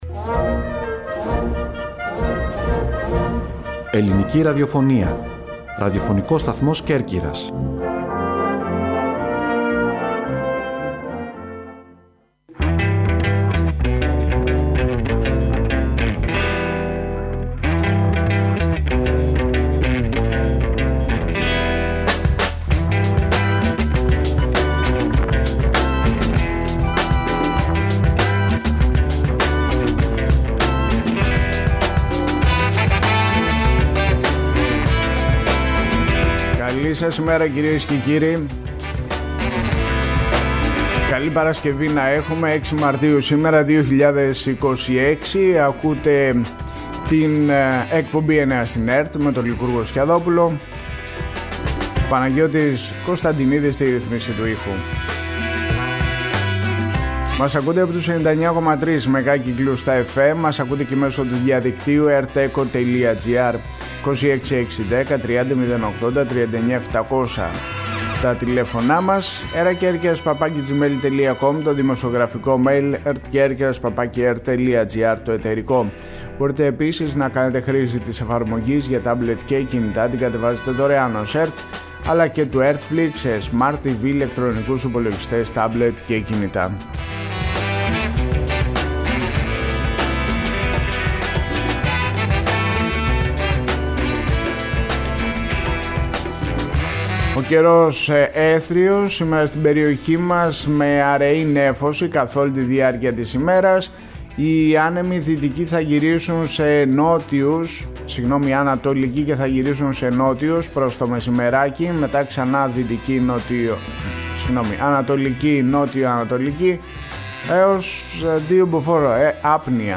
«Εννέα στην ΕΡΤ» Οριοθέτηση της ειδησιογραφίας στην Κέρκυρα, την Ελλάδα και τον κόσμο, με συνεντεύξεις, ανταποκρίσεις και ρεπορτάζ.